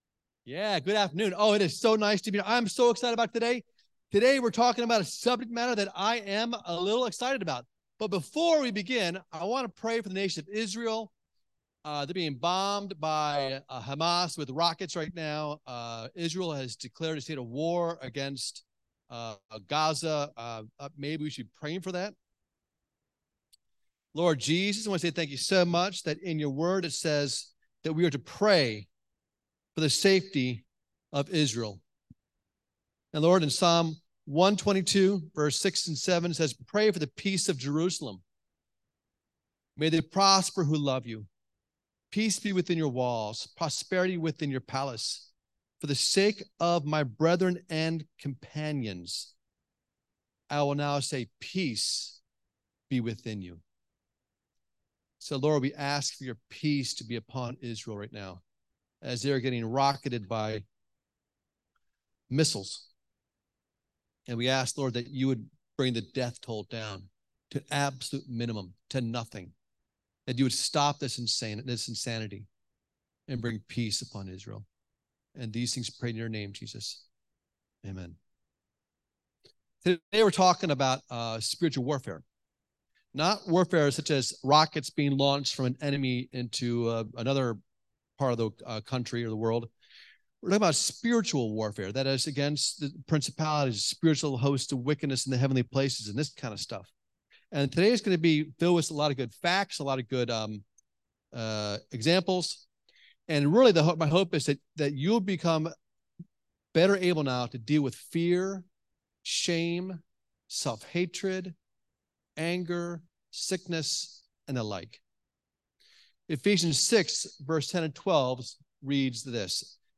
All Sermons The Spiritual Discipline of Spiritual Wrestling October 8, 2023 Series: Spiritual Wrestling , Spiritual Discipline Audio Download Notes Download Freedom from fear, shame, self-hatred, anger.